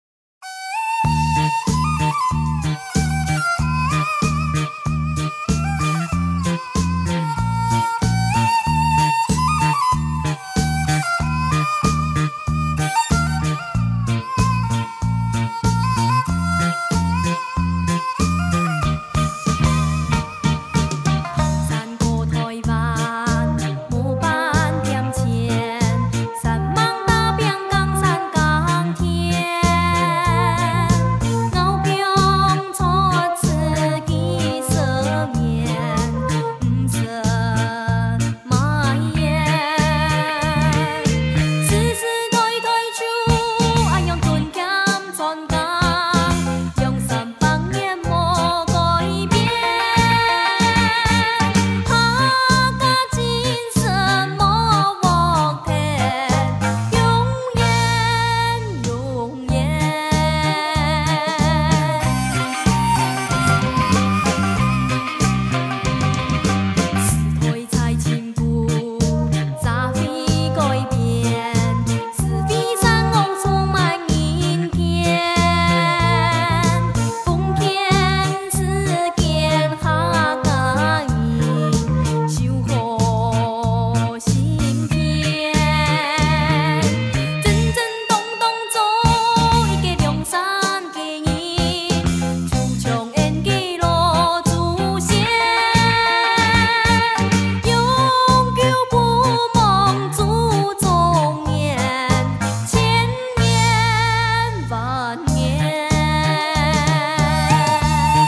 背景歌曲